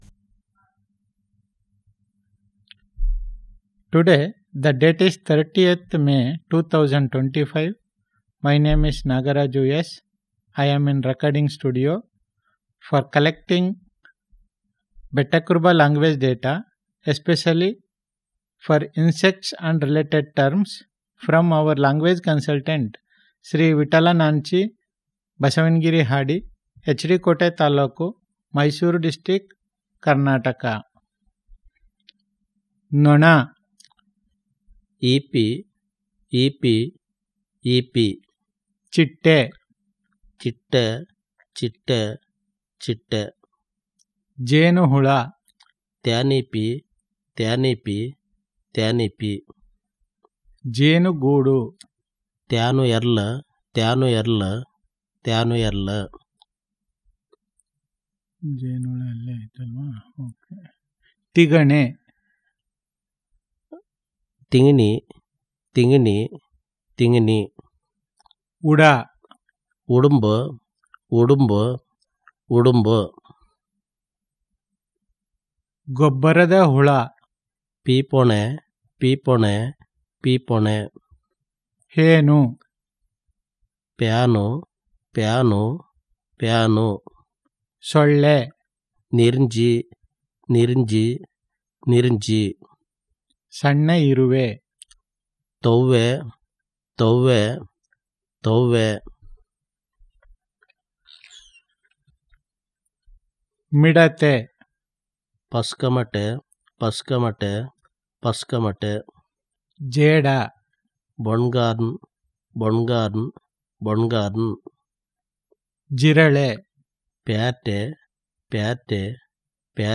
NotesThis is an Elicitation of Vocabularies on the domain of Insects and related